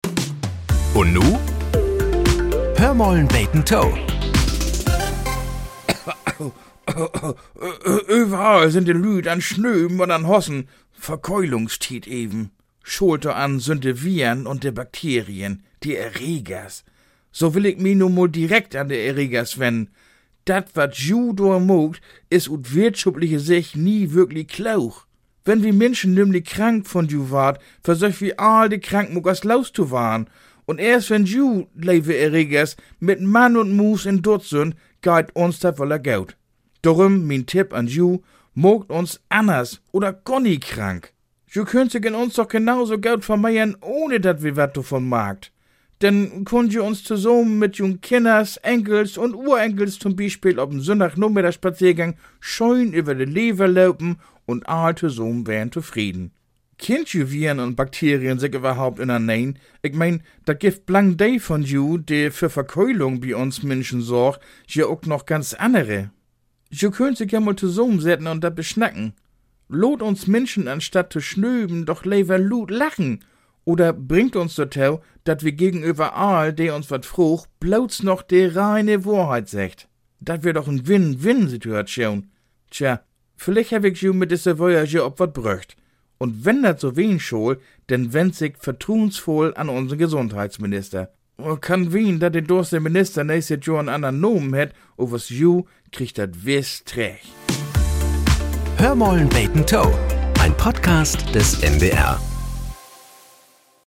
Nachrichten - 03.01.2025